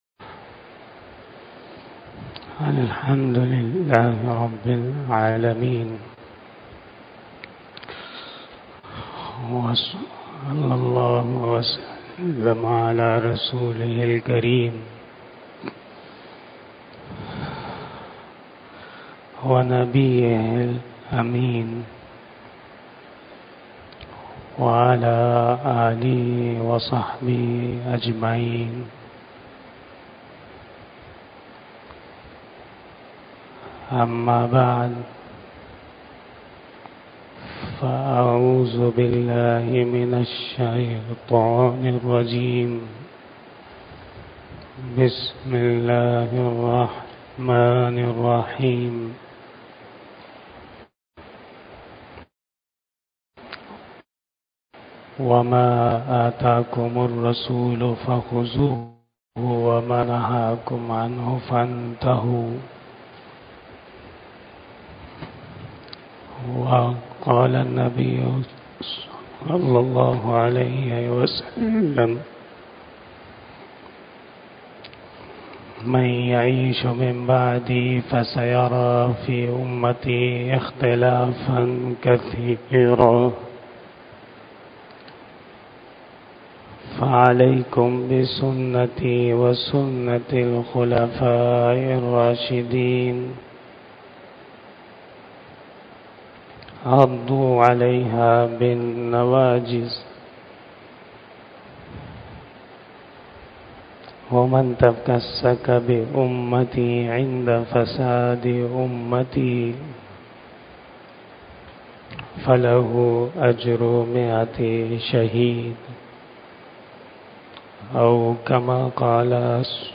39 Bayan E Jummah 29 September 2023 (12 Rabi Ul Awwal 1445HJ)